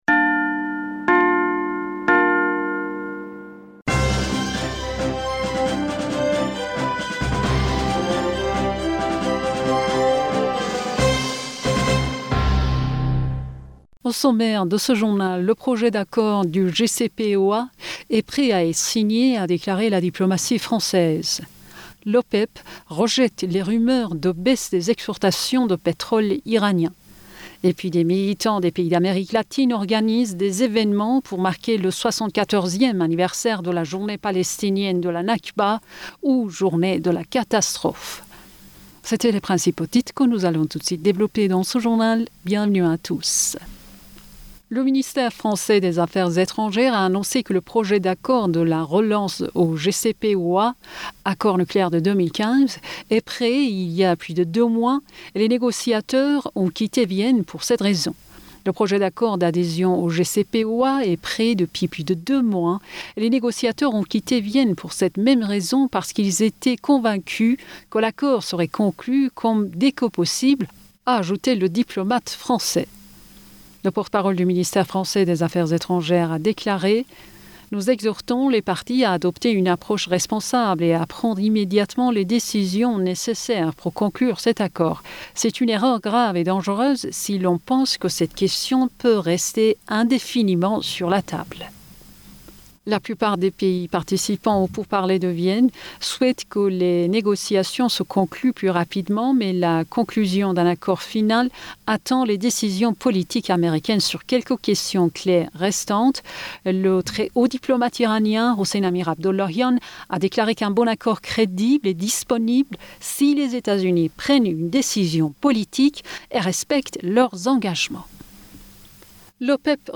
Bulletin d'information Du 18 Mai 2022